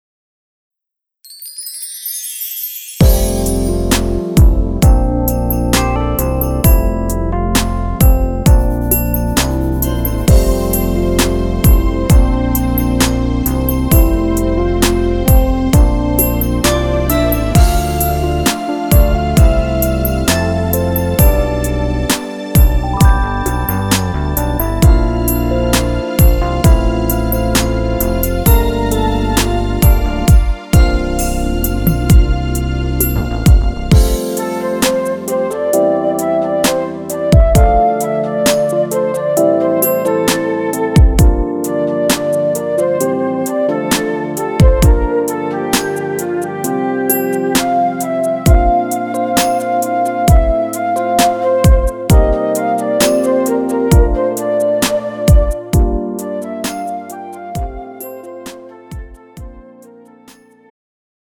음정 멜로디MR
장르 축가 구분 Pro MR